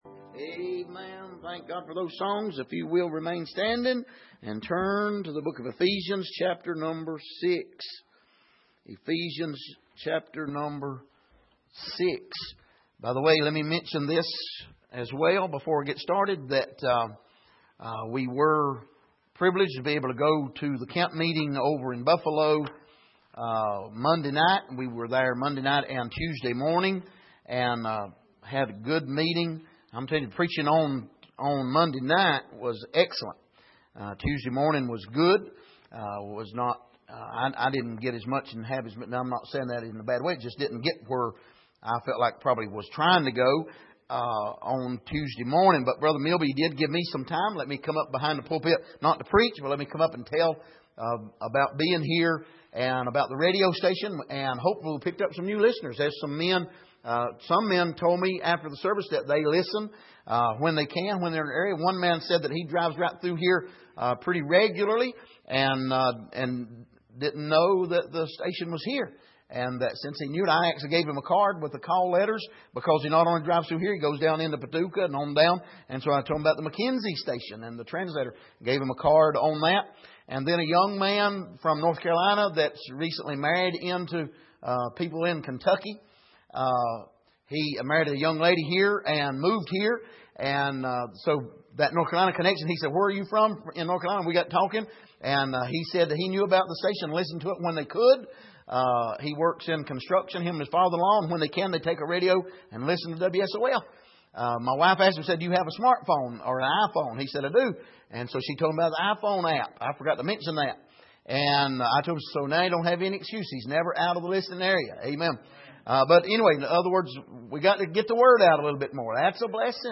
Miscellaneous Passage: Ephesians 6:17-20 Service: Midweek Prophetic Utterances Part 2 « Where Is The God of Judgment?